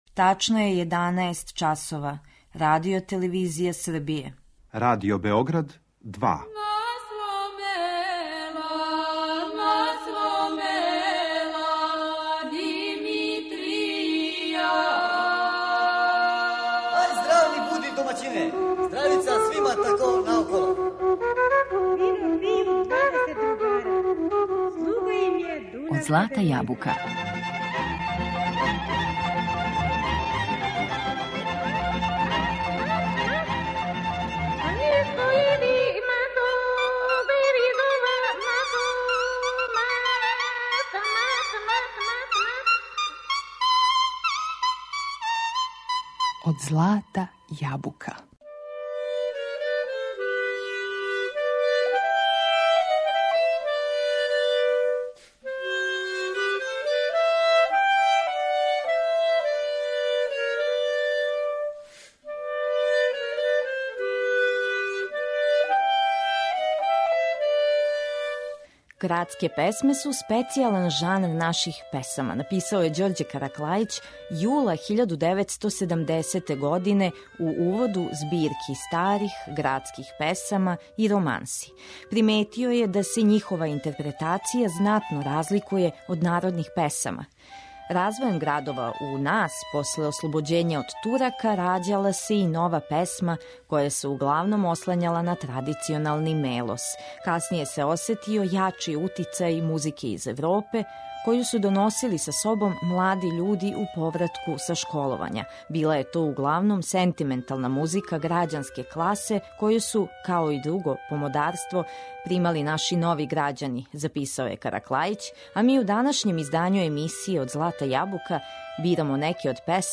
Градске песме
Била је то углавном сентиментална музика грађанске класе
у извођењу вокалних солиста уз пратњу Народног оркестра